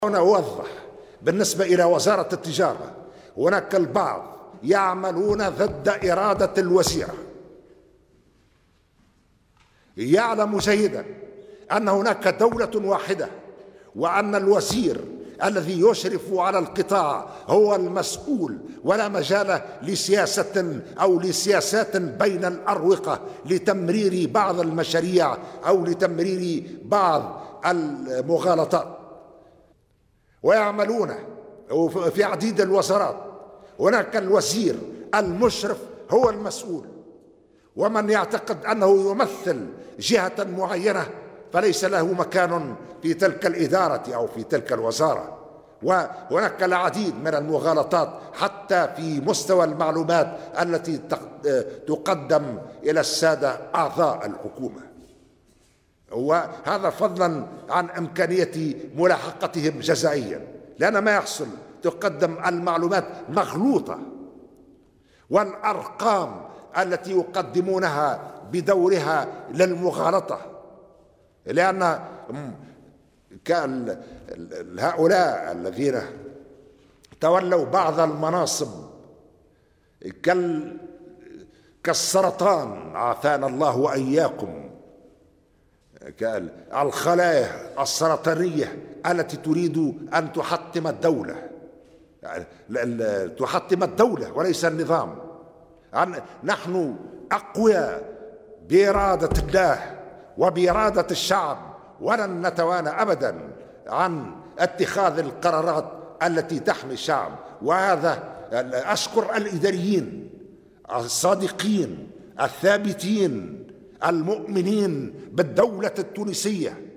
وأضاف خلال إشرافه اليوم الخميس على اجتماع مجلس الوزراء: " ليعلم الجميع أن هناك دولة واحدة وأن الوزير الذي يشرف على القطاع هو المسؤول ولا مجال لسياسات بين الأروقة لتمرير بعض المشاريع والمغالطات.. ومن يعتقد أنه يُمثل جهة معينة فلا مكان له في تلك الإدارة أو الوزارة.. يوجد العديد من المغالطات حتى في مستوى المعلومات التي تقدّم لأعضاء الحكومة وهناك إمكانية لملاحقتهم جزائيا".